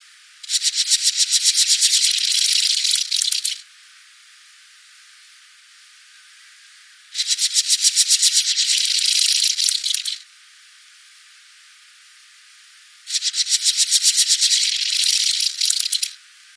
Акустические сигналы саранчового
Каждая фраза данного сигнала издается двумя различными способами.
Акустические сигналы: одиночный самец, Россия, Горный Алтай, Чемальский район, окрестности поселка Элекмонар, запись
Температура записи 26-28° С.